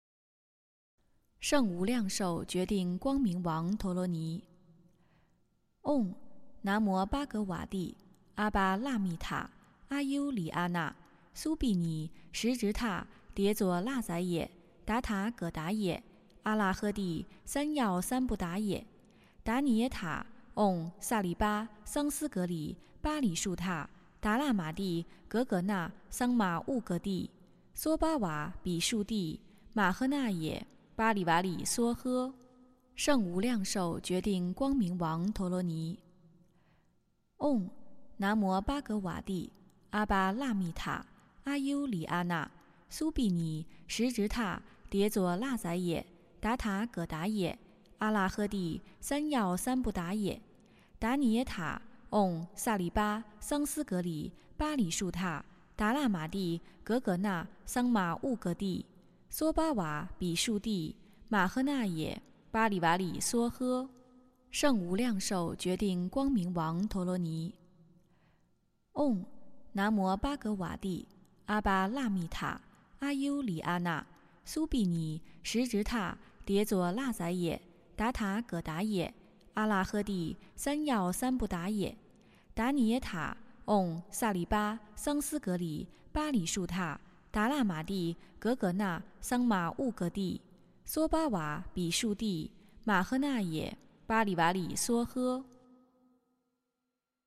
诵经
佛音 诵经 佛教音乐 返回列表 上一篇： 圆觉经-09净诸业障菩萨 下一篇： 佛说八关斋经 相关文章 职场31口和无诤--佛音大家唱 职场31口和无诤--佛音大家唱...